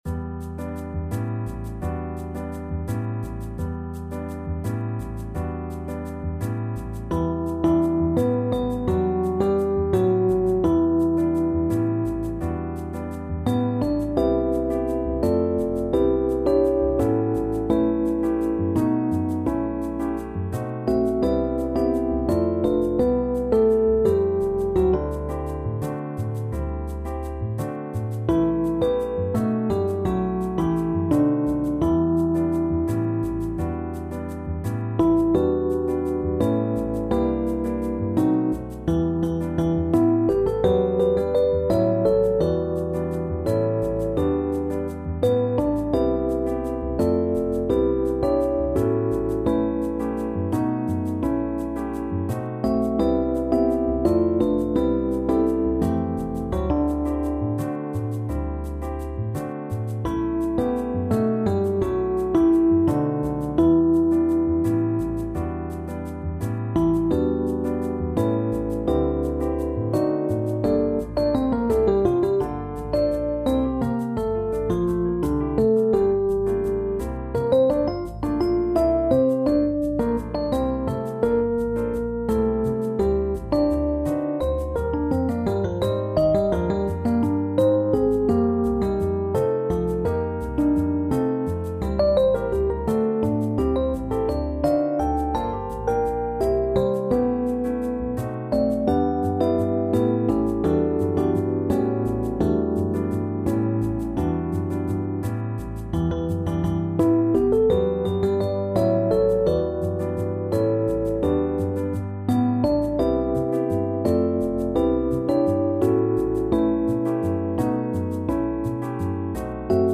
Volksmuziek
SSATB met piano | SATTB met piano
en dan ook nog in een 5/4 jazz-arrangement